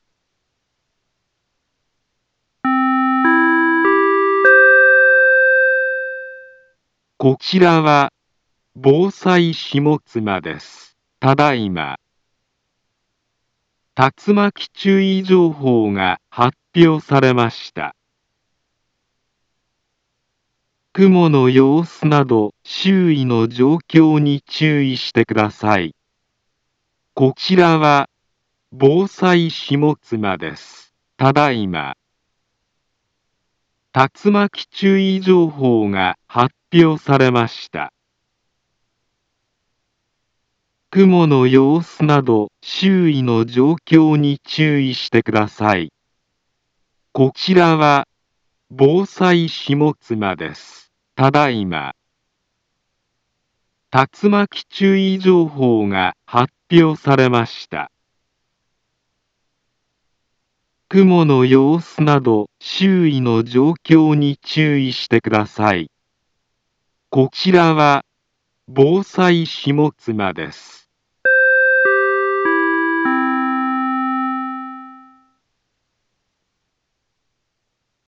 Back Home Ｊアラート情報 音声放送 再生 災害情報 カテゴリ：J-ALERT 登録日時：2024-07-25 17:24:32 インフォメーション：茨城県北部、南部は、竜巻などの激しい突風が発生しやすい気象状況になっています。